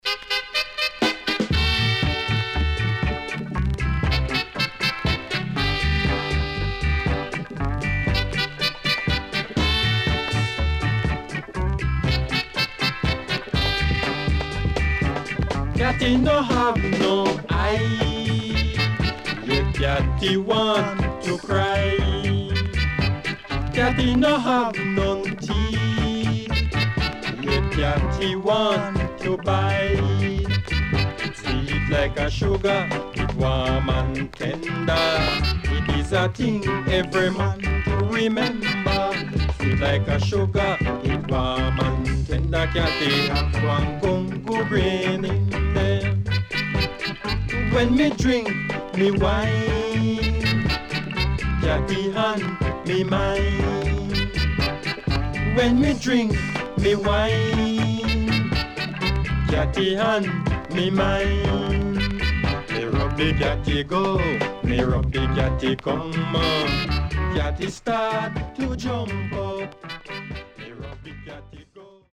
HOME > REGGAE / ROOTS  >  70’s DEEJAY
CONDITION SIDE A:VG(OK)
SIDE A:所々チリノイズがあり、少しプチノイズ入ります。